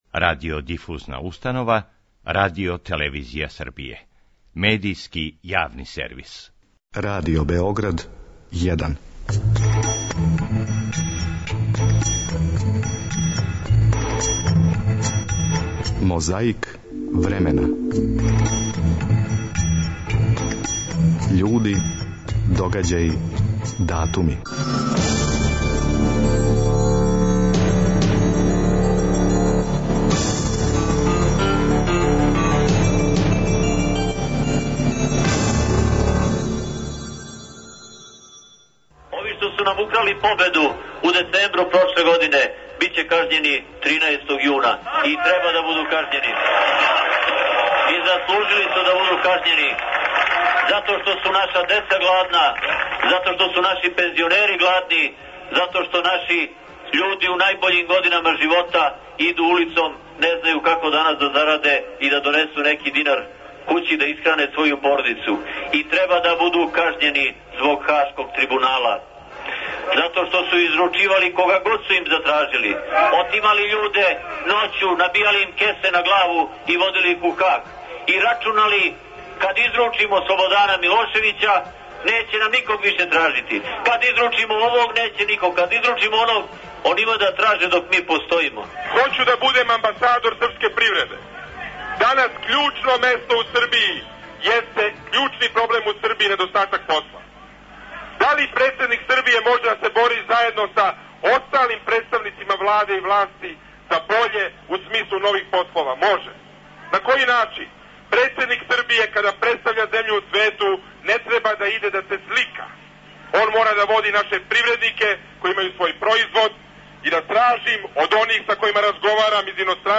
Народни херој Ђуро Салај сахрањен је у Загребу 22. маја 1958. године. Радио је овако извештавао.